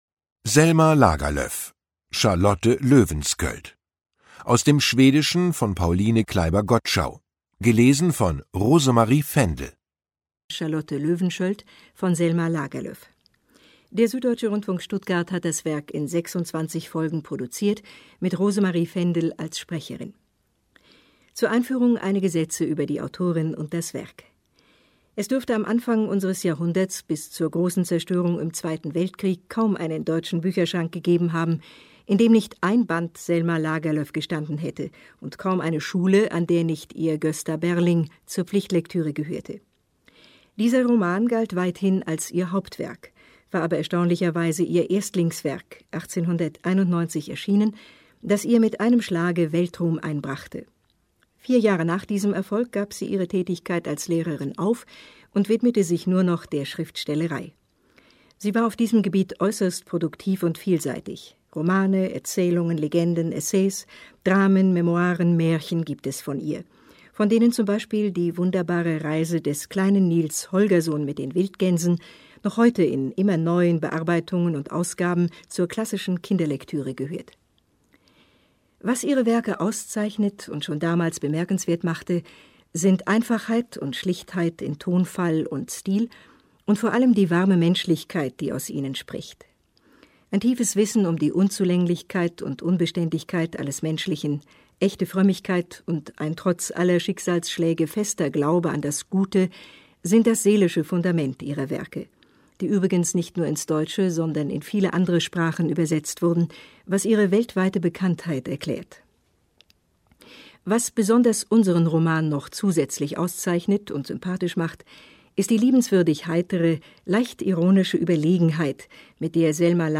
Ungekürzte Lesung mit Rosemarie Fendel (1 mp3-CD)
Rosemarie Fendel (Sprecher)